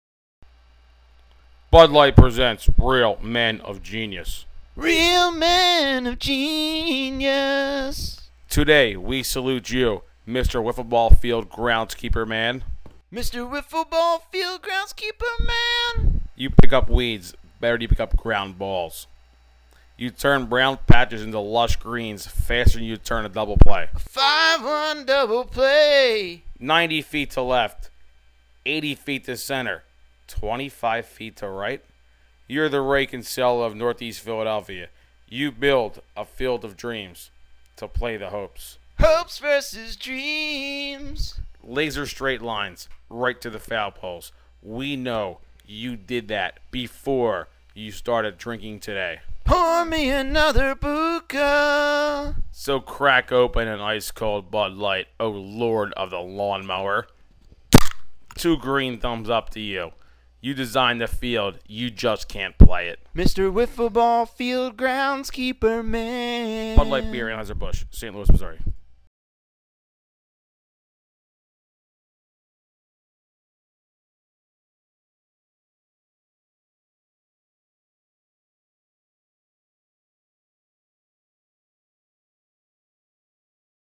Scud Light Beer Commercial